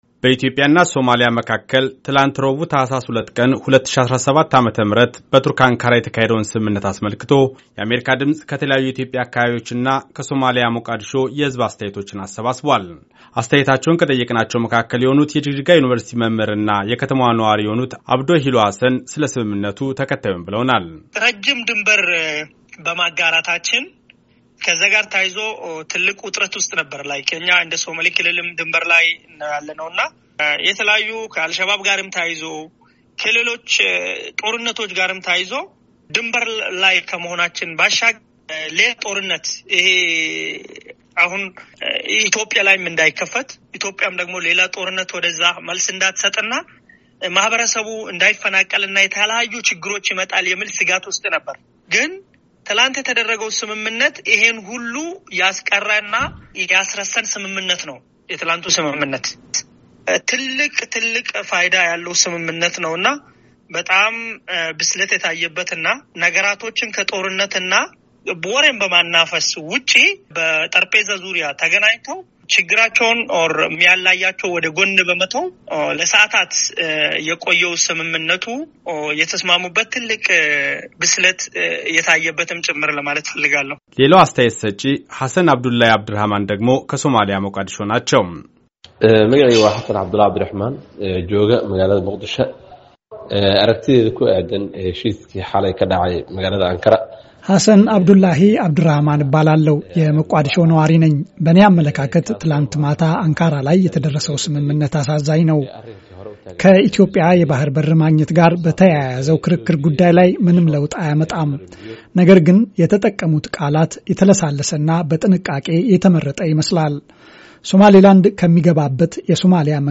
ኢትዮጵያ ከሶማሊያ ተለይታ የራስ-ገዝ አስተዳደር ከመሰረተችው ሶማሌላንድ ሪፐብሊክ ጋራ በተፈራረመችው አወዛጋቢ የባህር በር ግንኙነት ስምምነት ምክንያት አንድ ዓመት ለሚጠጋ ጊዜ ዲፕሎማሲያዊ ውዝግብ ውስጥ ገብተው የነበሩት ኢትዮጵያ እና ሶማሊያ አለመግባባታቸውን በስምምነት ለመፍታት ትላንት ረቡዕ ተፈራርመዋል። ስምምነቱን በተመለከተ፣ በኢትዮጵያ የተለያዩ ከተሞች የሚኖሩና የመቋድሾ ነዋሪዎችን ጠይቀናል። አስተያየታቸውን ከተያያዘው ፋይል ይከታተሉ።